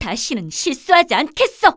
cleric_f_voc_skill_relicofmiracle.wav